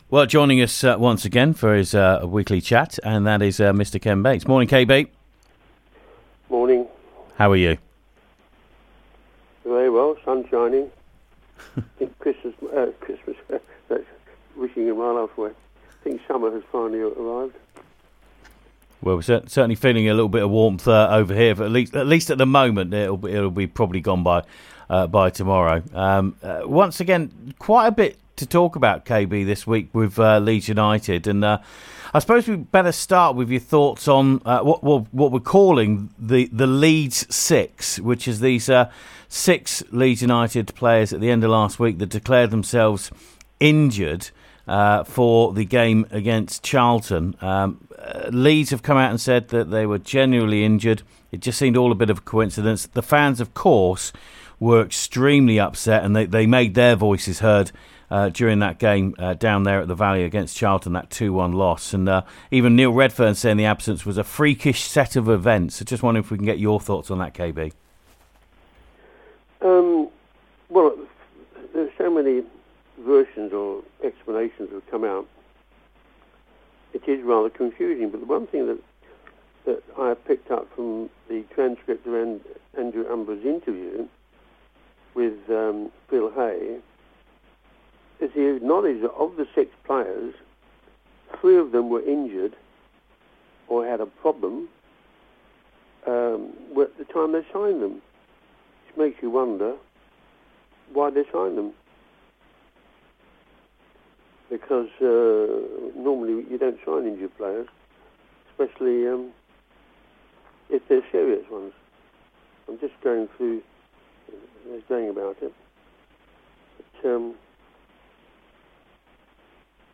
The Ken Bates Interview